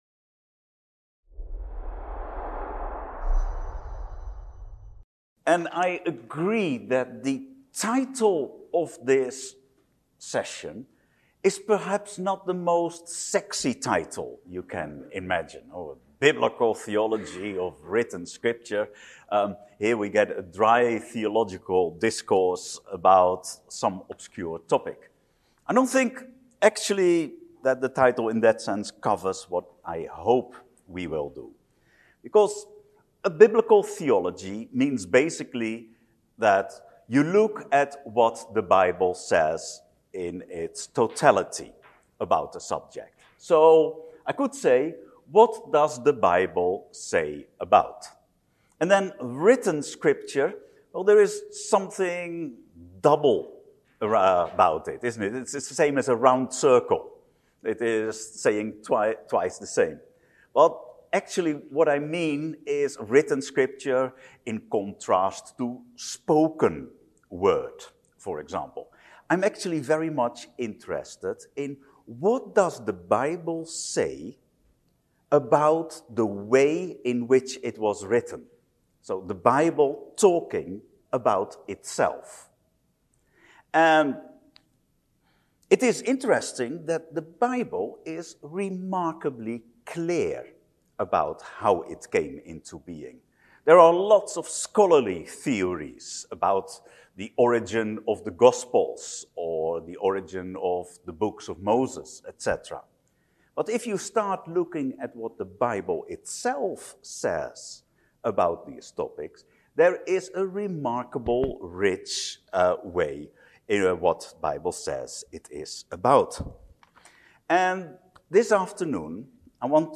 The attitude towards Scripture, and even towards any authoritative text, is changing for a variety of reasons. In this talk, we begin to analyse what is happening in society and the church and also explore the Bible to learn which of its qualities need to be
Event: ELF Workshop